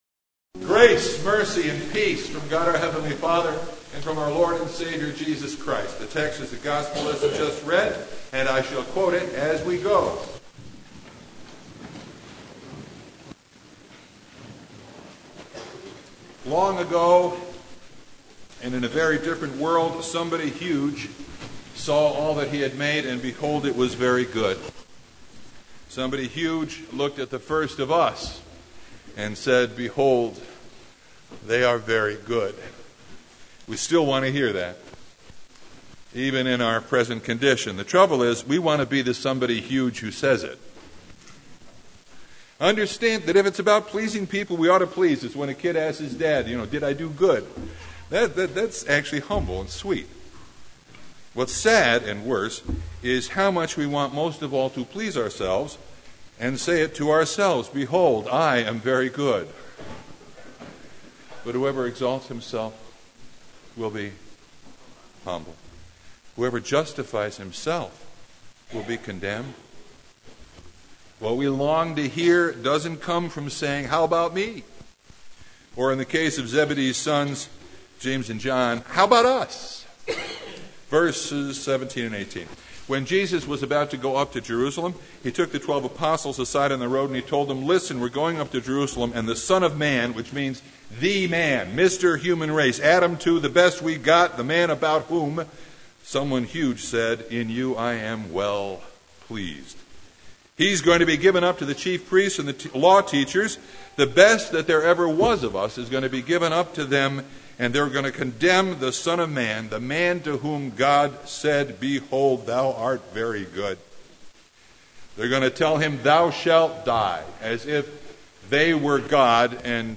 Passage: Matthew 20:17-28 Service Type: Sunday
Sermon Only